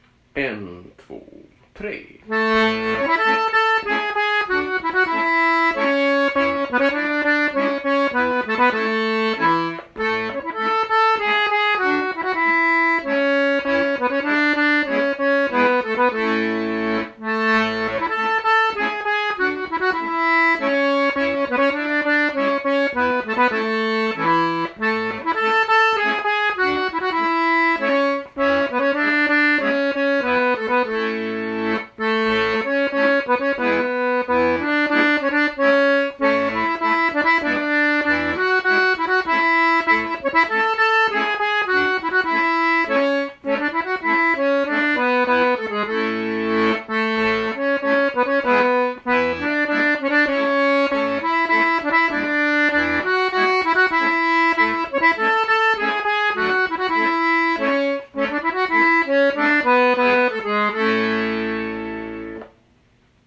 Polska efter Matses Anders Norman, Yttre Heden, Säter [NOTER]